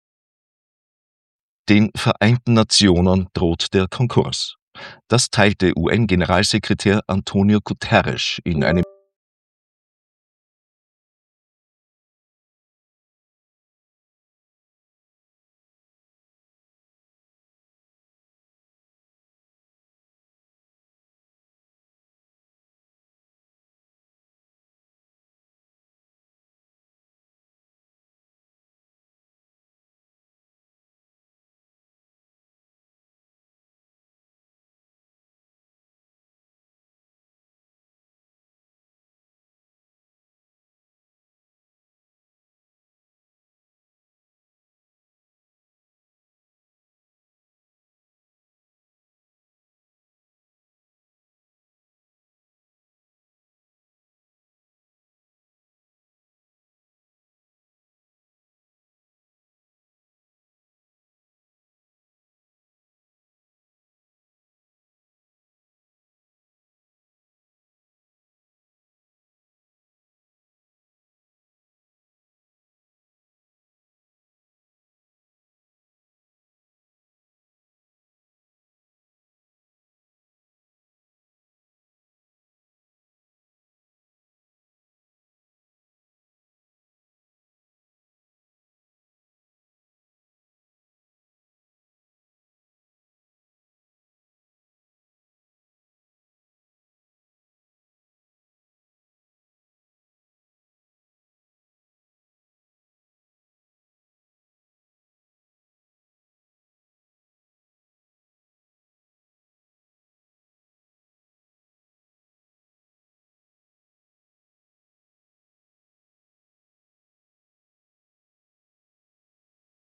Kontrafunk Wochenrückblick – Nachrichten vom 31.1.2026